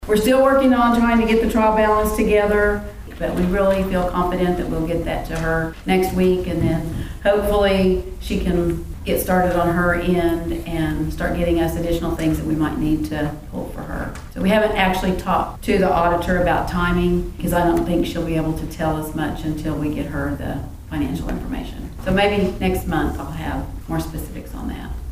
At Thursday evening's council meeting in Pawhuska, City Manager Carol Jones gave an update on the city's financial status and the progress being made on the 2024 fiscal year audit.